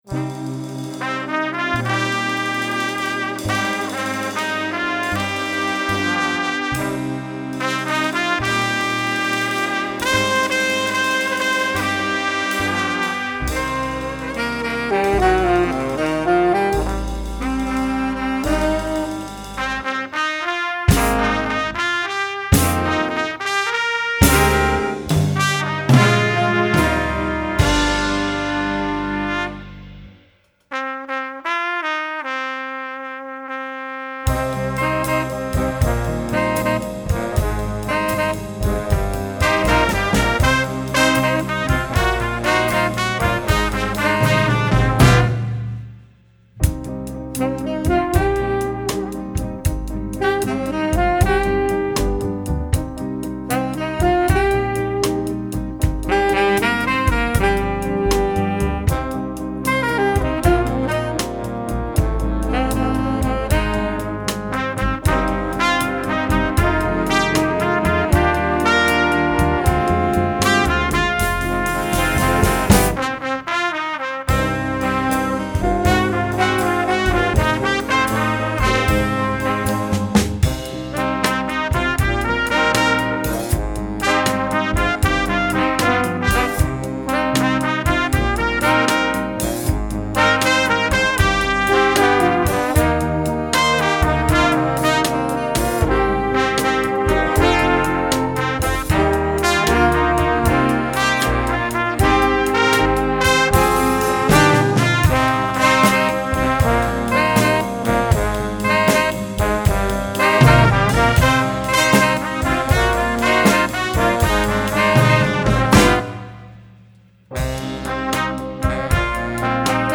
Voicing: Little Big Band